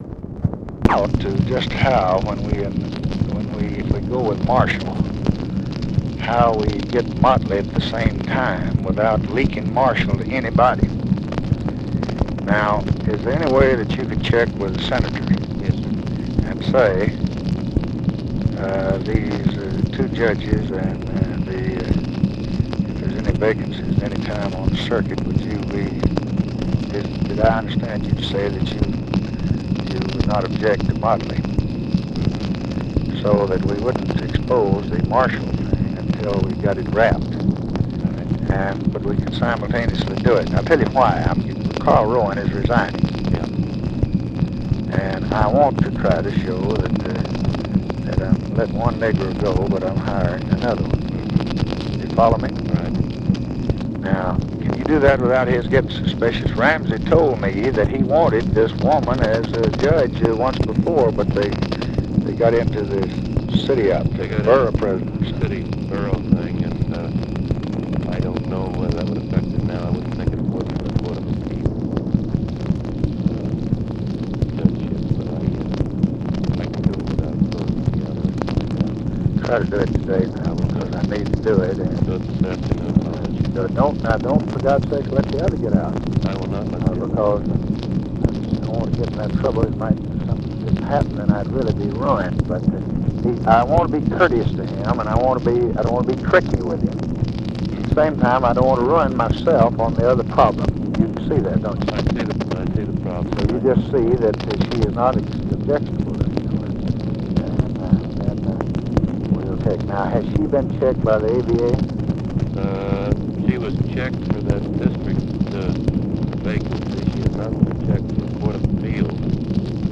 Conversation with NICHOLAS KATZENBACH, July 9, 1965
Secret White House Tapes